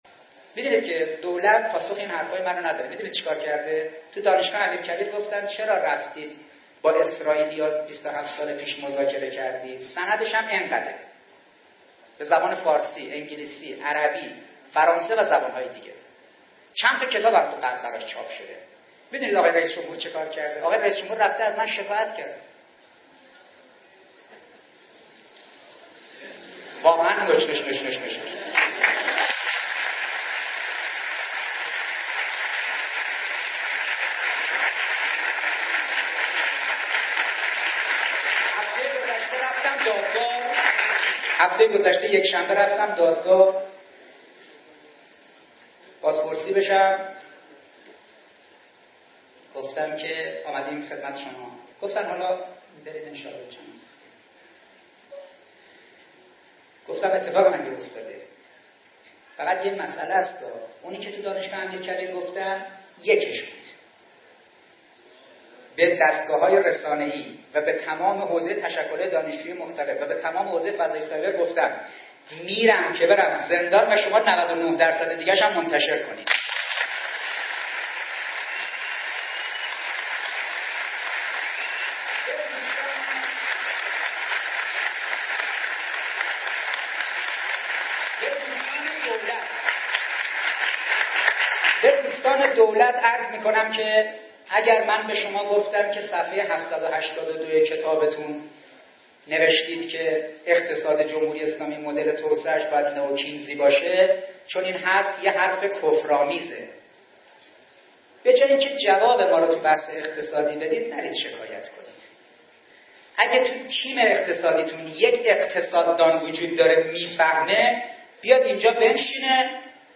دانلود سخنرانی حسن عباسی با موضوع شکایت حسن روحانی از ایشان